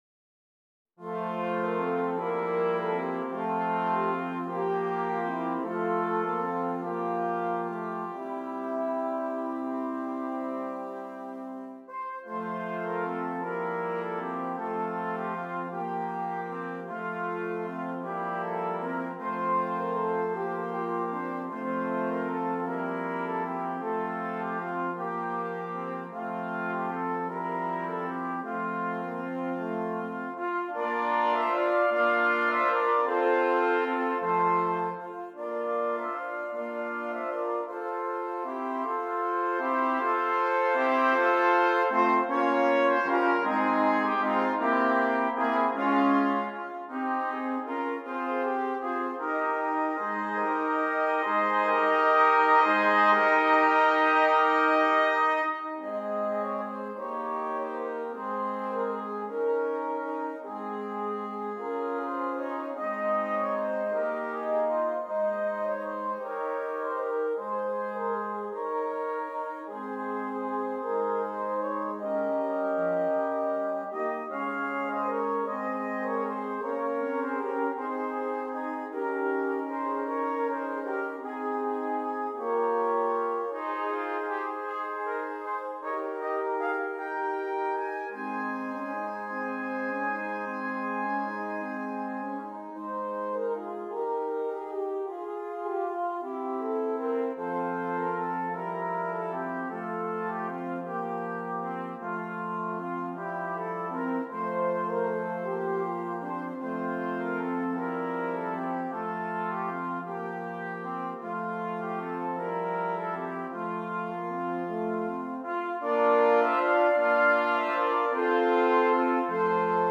6 Trumpets
This beautiful and flowing Christmas carol